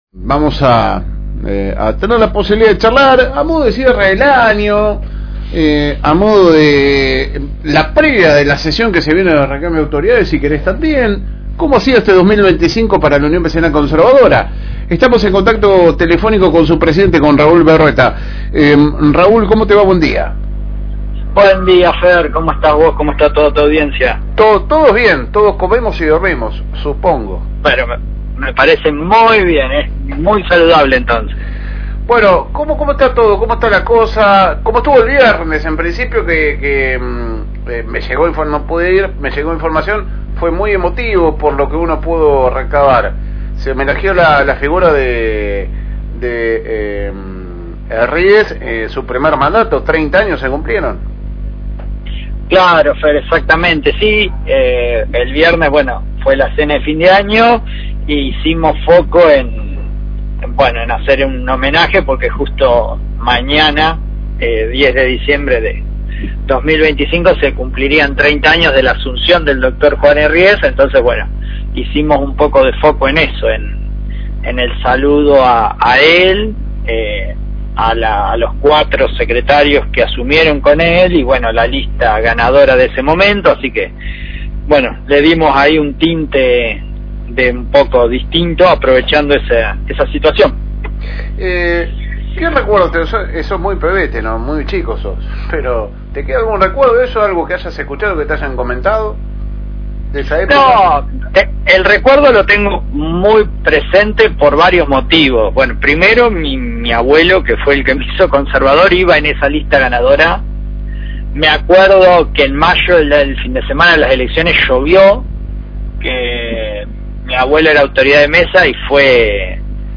La entrevista concluyó con un mensaje de optimismo sobre el futuro de la UVC y del partido.